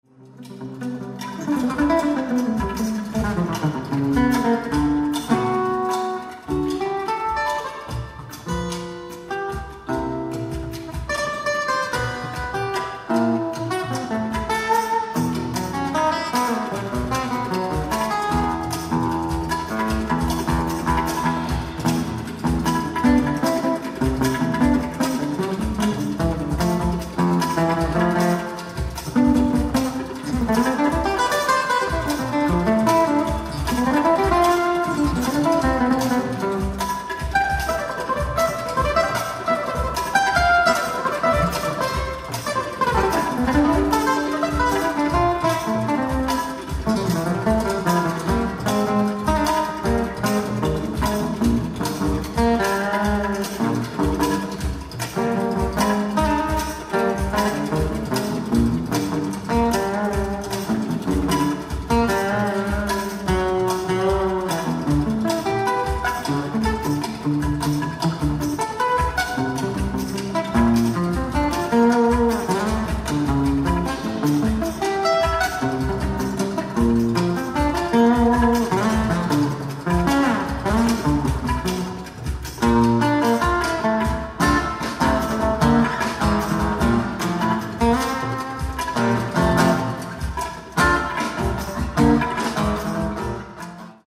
ライブ・アット・ベエルシェバ、イスラエル 06/20/2022
※試聴用に実際より音質を落としています。